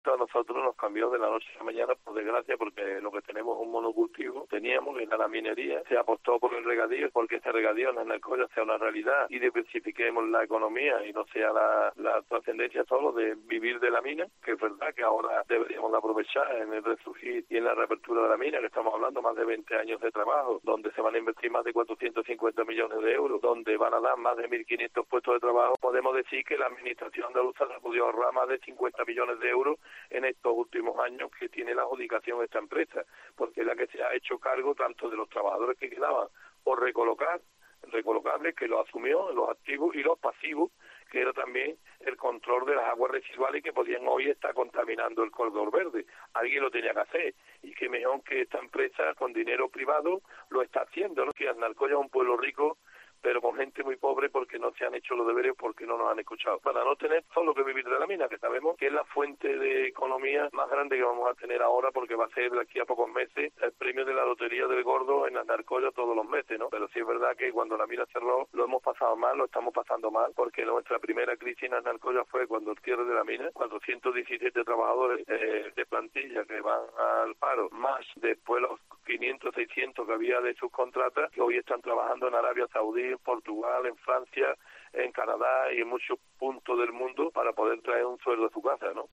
Así lo ha manifestado en declaraciones a COPE Sevilla Juan José Fernández, alcalde de Aznalcóllar y antiguo minero, quien asegura que la explotación va a generar 1500 puestos de trabajo para los próximos veinte años.